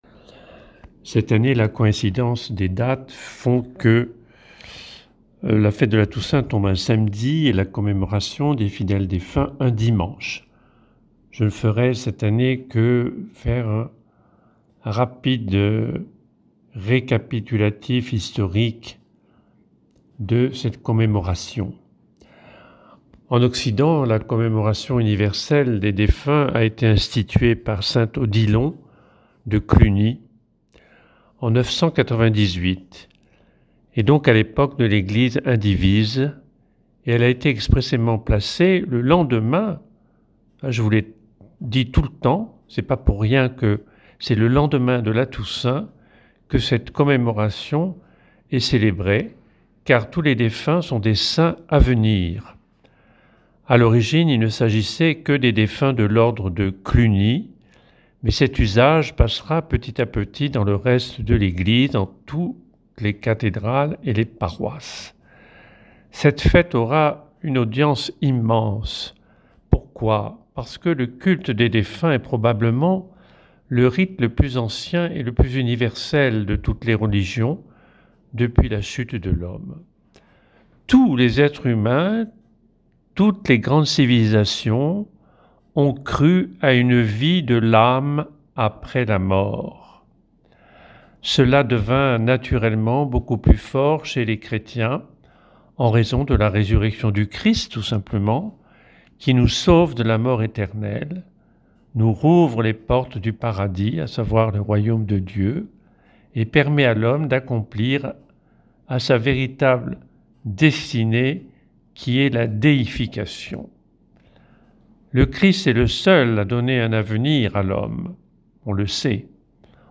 Méditation